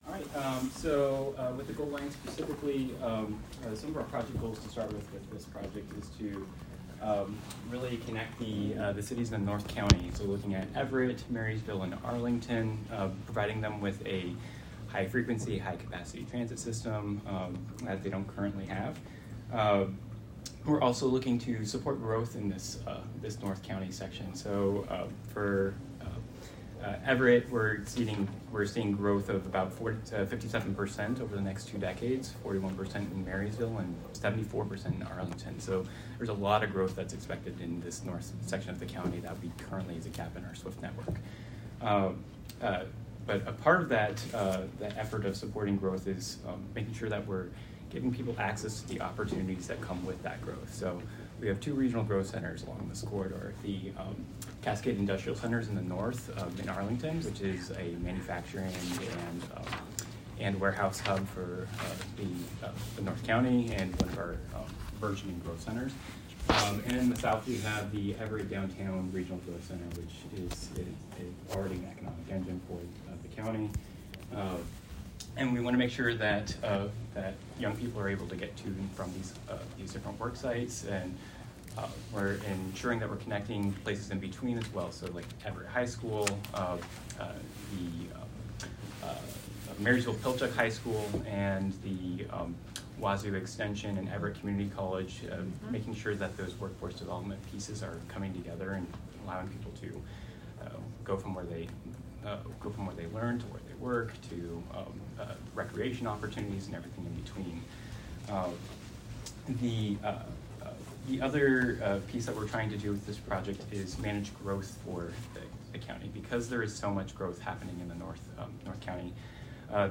***PHOTOS AND B-ROLL HERE; AUDIO OF ROUNDTABLE DISCUSSION HERE***
CT-Roundtable.m4a